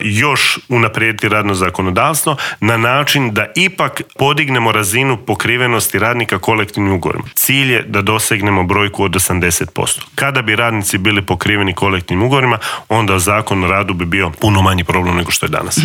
ZAGREB - Gostujući u Intervjuu tjedna Media servisa ministar rada, mirovinskoga sustava, obitelji i socijalne politike Marin Piletić otkrio je detalje pregovora sa sindikatima oko povišica, ali se osvrnuo i na najavu zabrane rada nedjeljom, kritikama na novi Zakon o radu, kao i o novostima koje stupaju na snagu 1. siječnja, a tiču se minimalne plaće i mirovina.